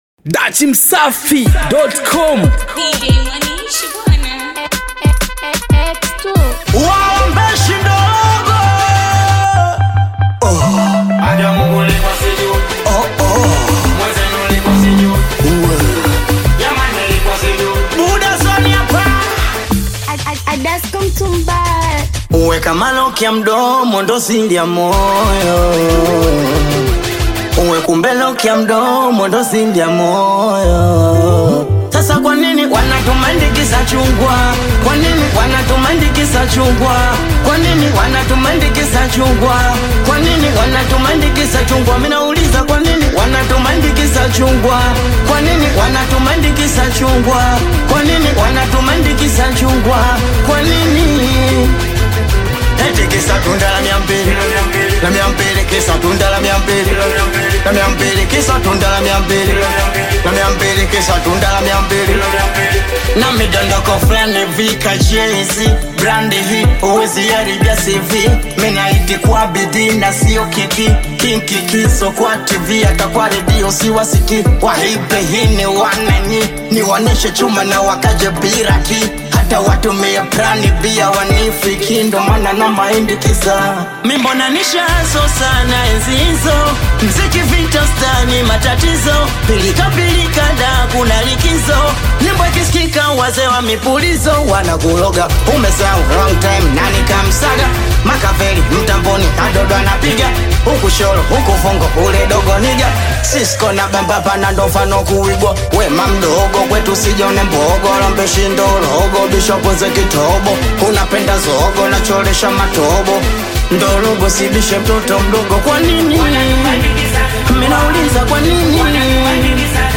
Singeli Bakora